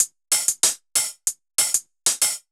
Index of /musicradar/ultimate-hihat-samples/95bpm
UHH_ElectroHatD_95-01.wav